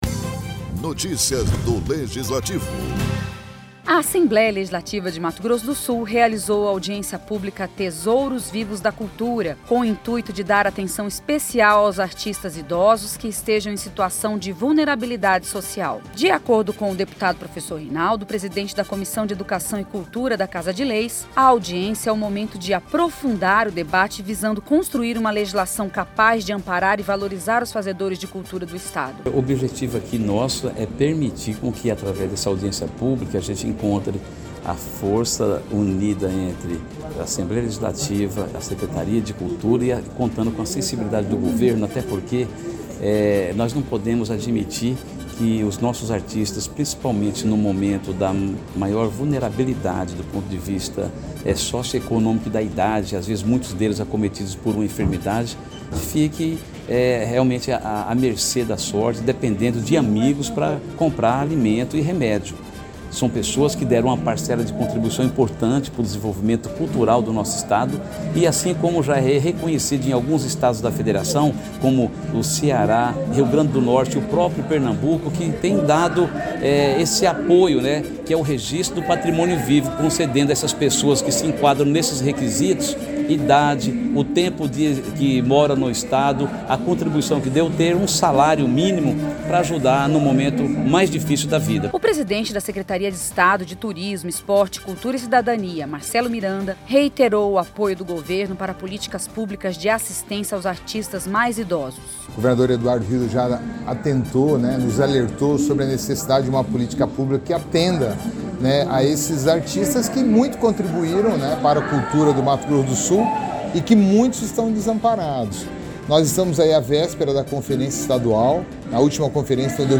Assembleia Legislativa de Mato Grosso do Sul (ALEMS), realizou uma sessão solene para entrega de diploma em comemoração ao Dia Estadual do Repórter Fotográfico - Valdenir Rezende, o evento foi proposto pelo deputado Marcio Fernandes (MDB).